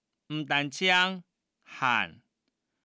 Hakka tts 用中文字典方式去mapping客語語音 客語語音來源 1.